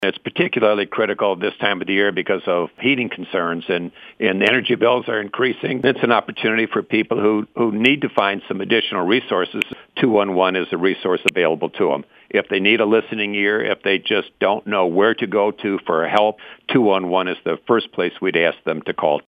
That’s commissioner Norm Saari.